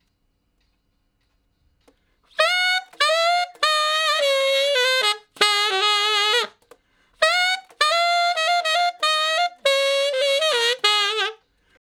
068 Ten Sax Straight (Ab) 09.wav